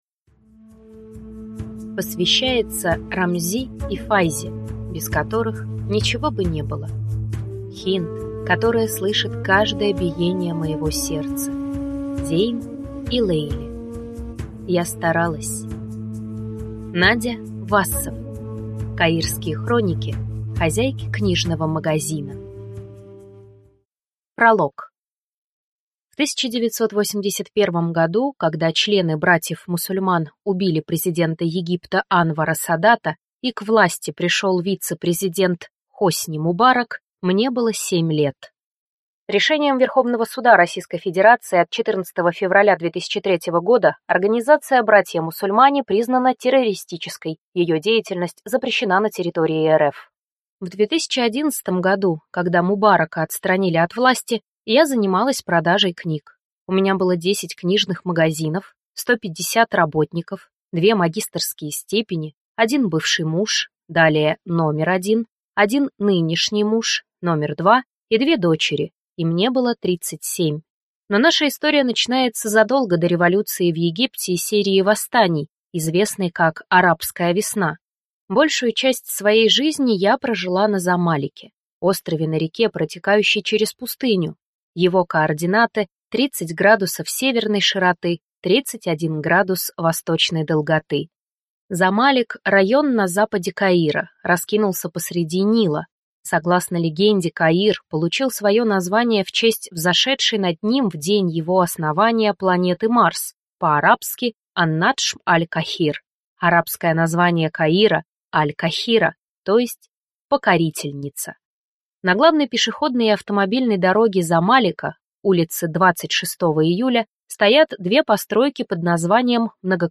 Аудиокнига Каирские хроники хозяйки книжного магазина | Библиотека аудиокниг